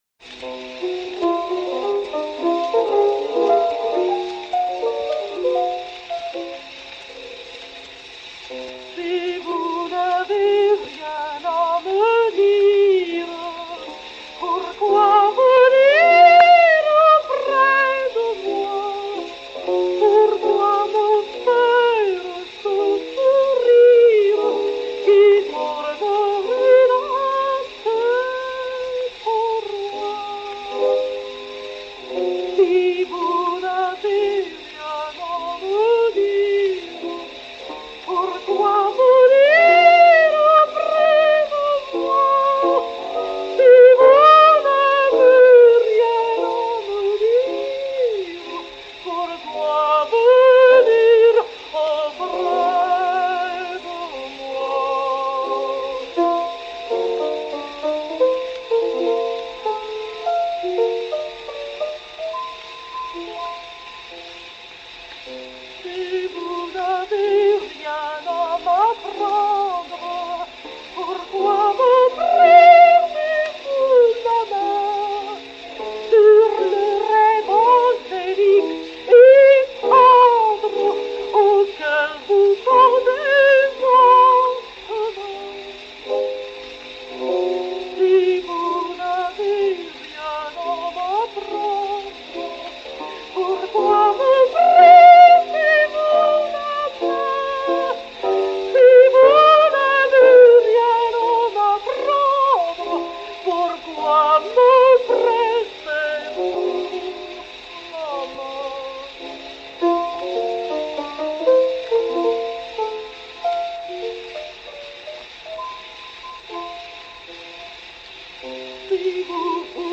mélodie (Victor Hugo / baronne Willy de Rothschild)
Adelina Patti et Sir Landon Ronald au piano
enr. à Craig-y-Nos Castle en décembre 1905